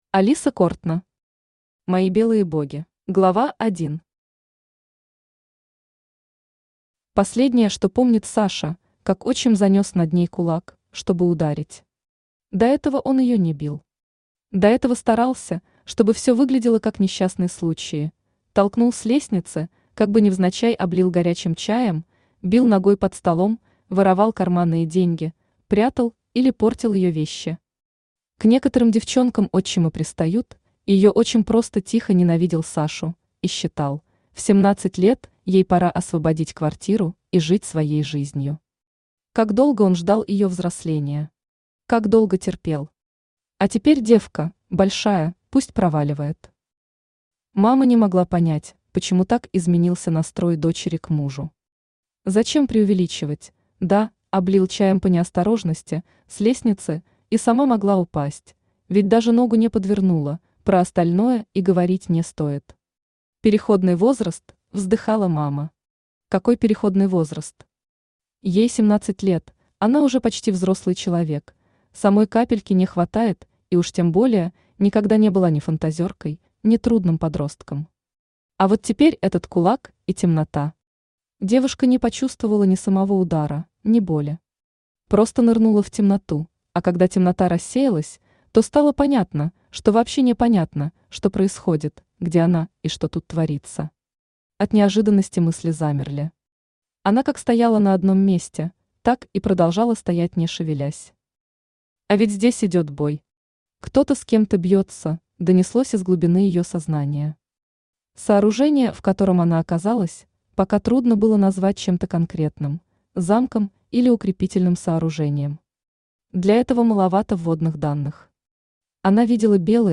Аудиокнига Мои Белые Боги | Библиотека аудиокниг
Aудиокнига Мои Белые Боги Автор Алиса Кортно Читает аудиокнигу Авточтец ЛитРес.